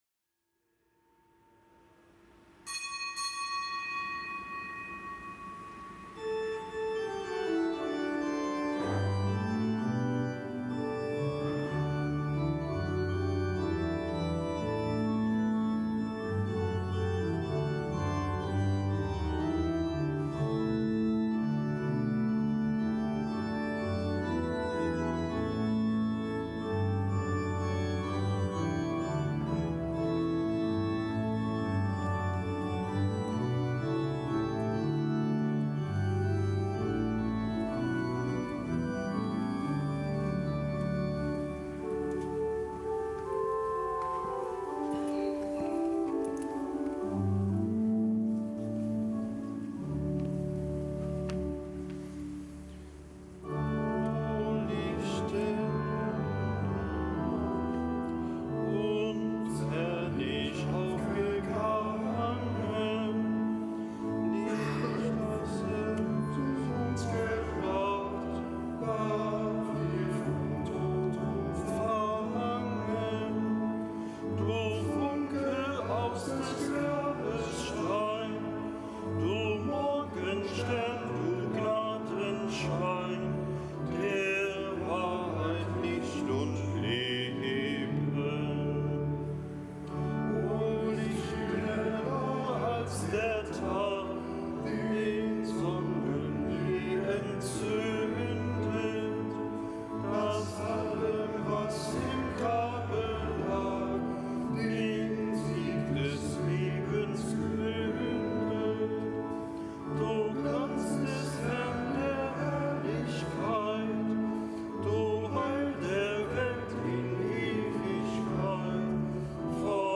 Kapitelsmesse aus dem Kölner Dom am Dienstag der dritten Osterwoche.